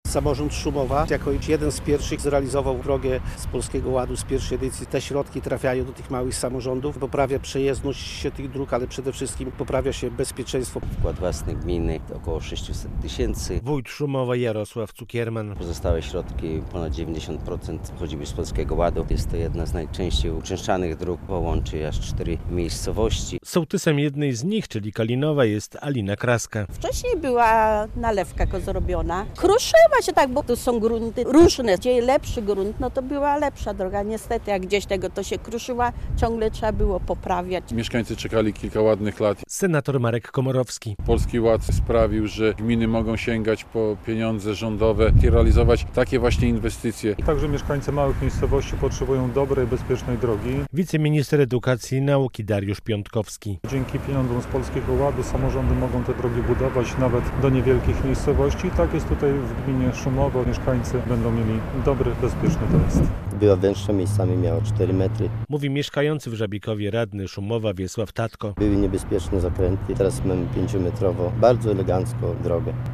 Łączy cztery wsie z krajową ósemką. Nowa droga w gminie Szumowo - relacja